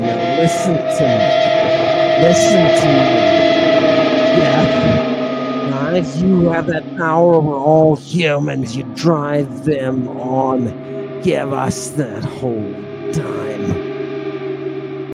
electric guitar, bending strings, sounds like whales. slow. reverb+delay.